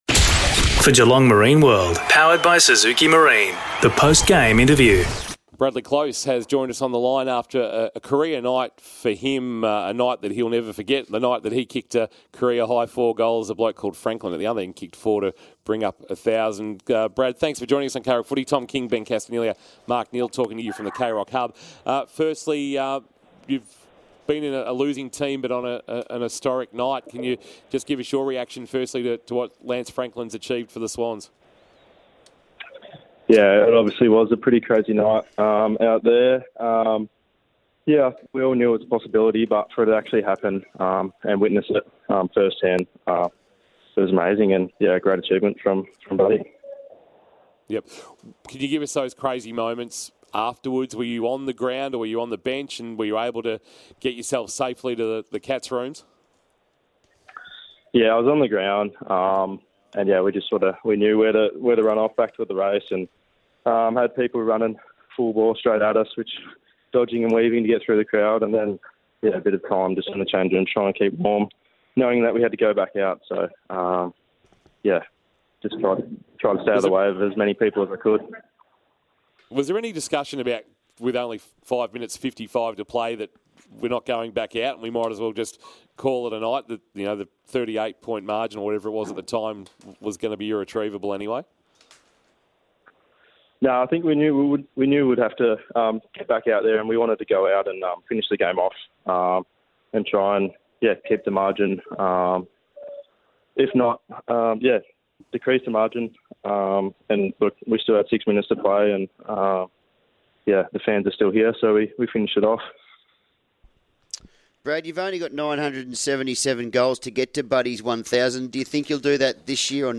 2022 - AFL ROUND 2 - SYDNEY vs. GEELONG: Post-match Interview - Brad Close (Geelong)